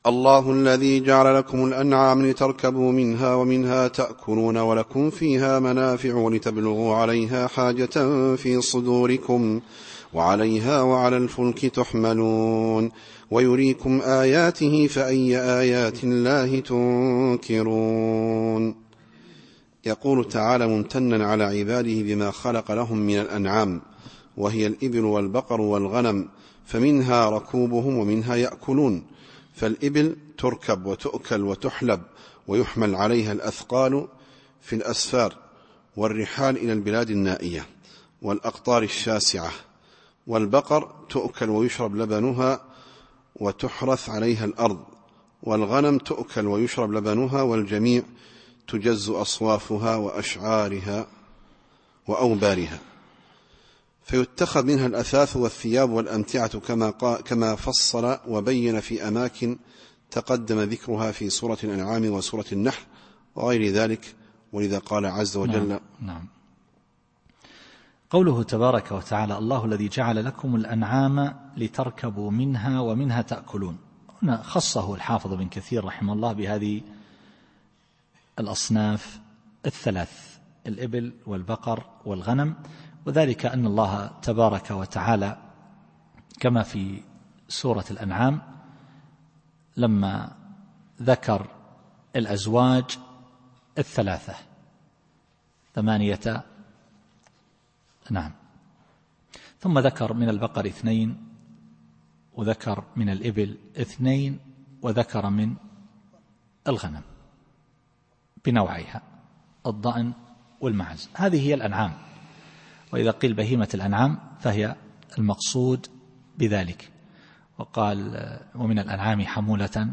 التفسير الصوتي [غافر / 79]